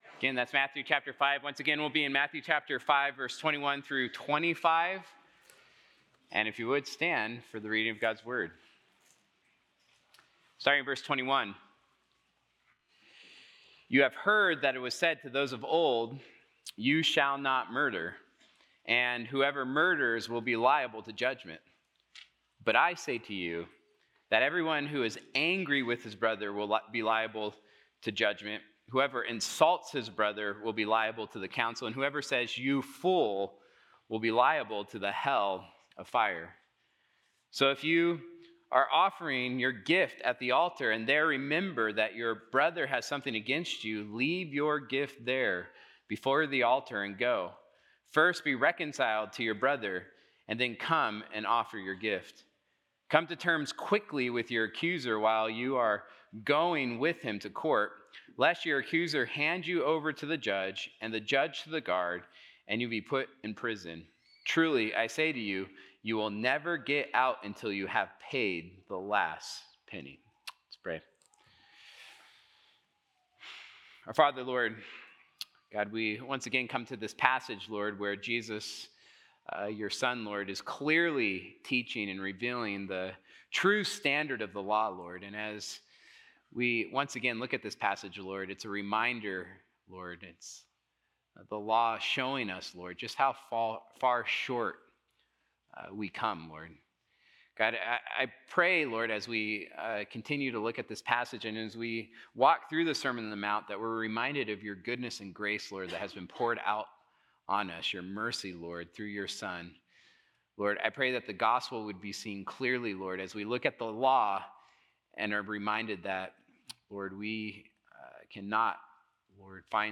Sunday-Sermon-September-7-2025.mp3